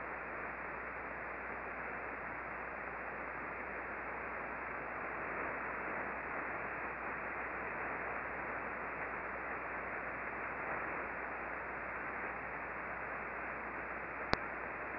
We used two Icom R-75 HF Receivers, one tuned to 19.432 MHz (LSB), corresponding to the Red trace in the charts below, and the other tuned to 20.342 MHz (LSB), corresponding to the Green trace.
The antenna was an 8-element log periodic antenna pointed at a fixed azimuth of 79 degrees true (no tracking was used).
We observed mostly S-bursts but some sounded like fast L-bursts.
Click here for a stereo audio file corresponding to the time period 0719:45 to 0720:00